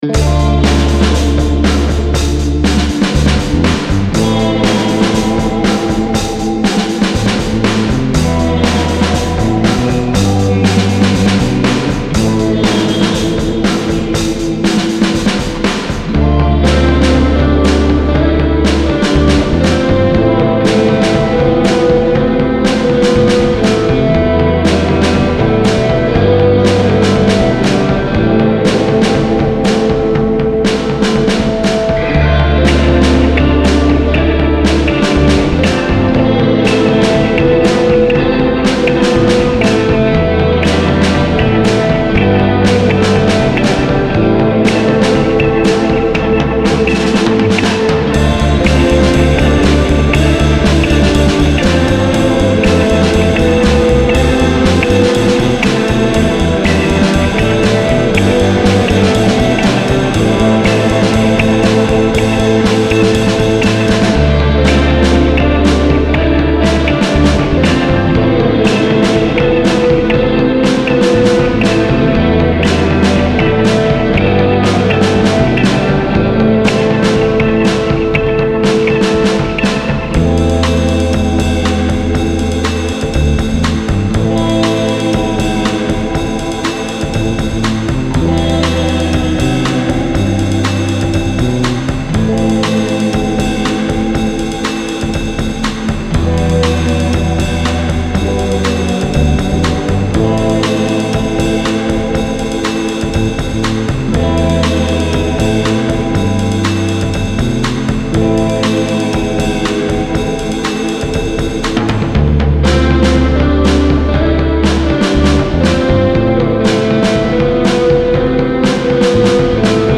Style Style Oldies, Rock
Mood Mood Cool, Relaxed
Featured Featured Bass, Drums, Electric Guitar +1 more
BPM BPM 120